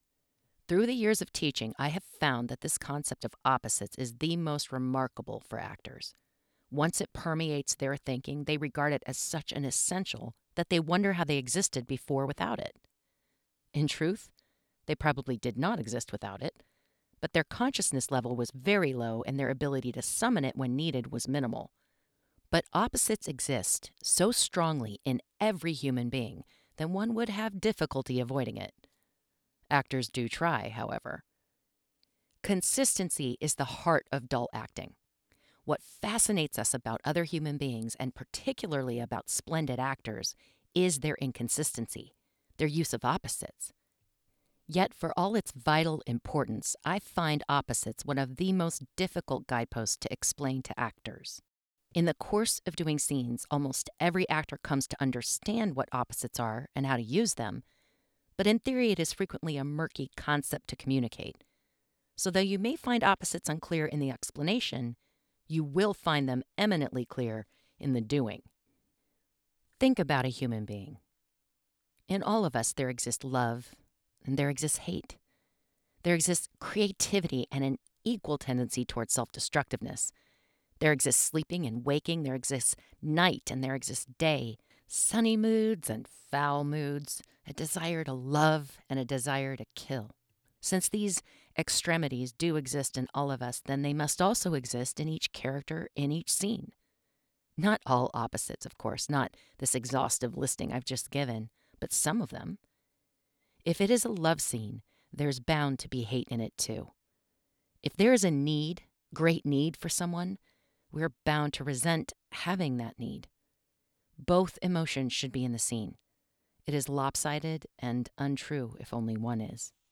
NON-fiction, how to
1st Person
Home Recording Studio:
• PD70 Dynamic Broadcast Mic
Non-Fiction-1st-Person-Opposites-for-Actors.wav